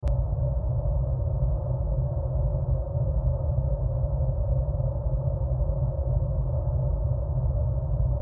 دانلود صدای قایق 10 از ساعد نیوز با لینک مستقیم و کیفیت بالا
جلوه های صوتی